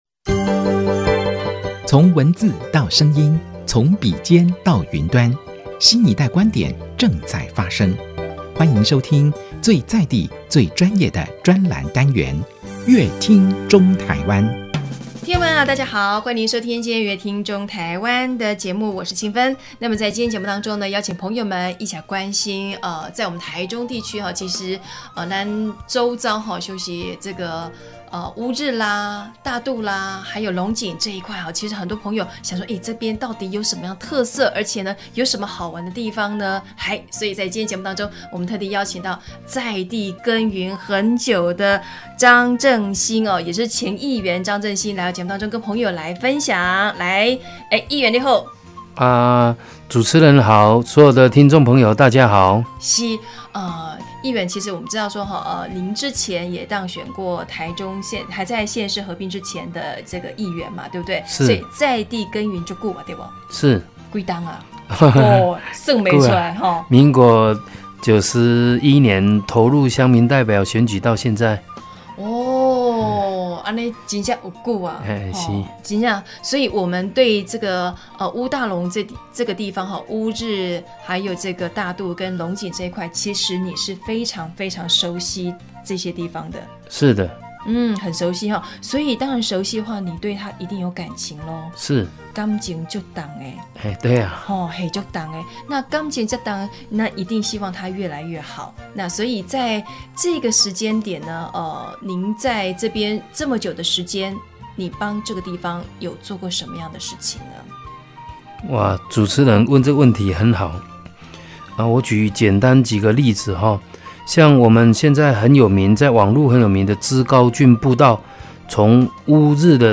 張正興前議員一談到對在地的建設與看法時，語氣時而激昂、時而溫柔，流露他對在地的情感，他用雙雙腳走遍三十多個里，傾聽民眾的需求，更因為了解烏大龍地區，看到不利這個地方發展的焚化場或污水處理廠設想要在此落腳更是心痛，捍衛在地之心油然而生，於是張正興勇敢再次挑戰市議員大位，希望用自己努力的心繼續為鄉親服務，所以在今天的節目中特地邀請張正興分享他如何懷抱初心，為這塊土地尋找改變契機的心路歷程。